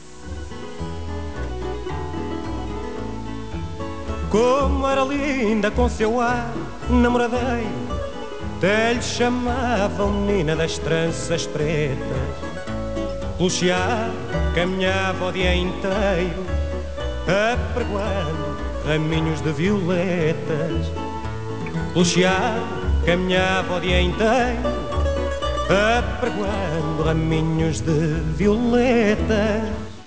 E já agora, vai um fadinho?